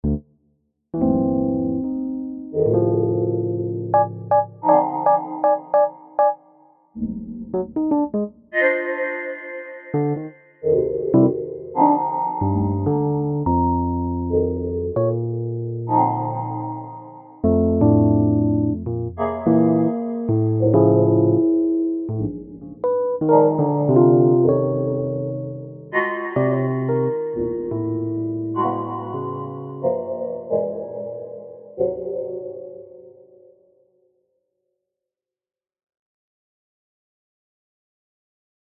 Cubase5,Casio CTK-650,Technics SL-1200MK5,SP-404sx,windows7